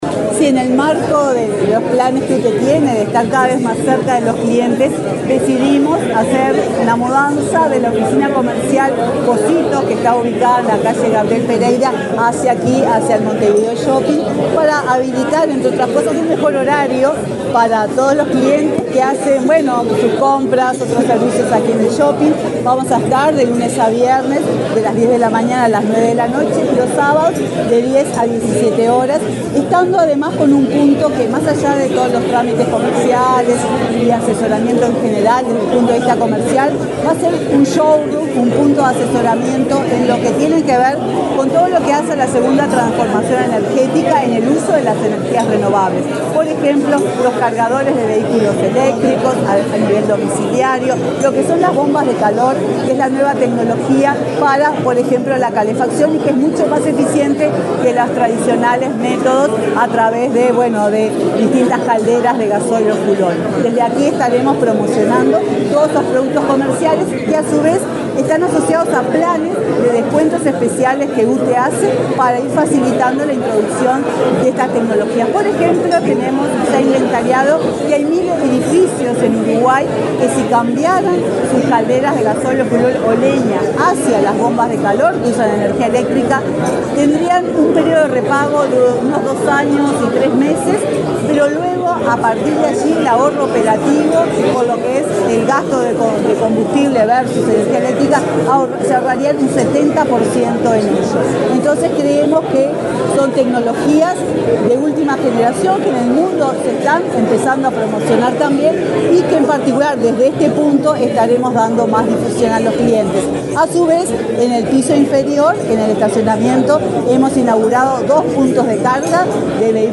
Declaraciones de la presidenta de UTE, Silvia Emaldi
La presidenta de la UTE, Silvia Emaldi, dialogó con la prensa, luego de participar en el acto de inauguración de una oficina comercial del organismo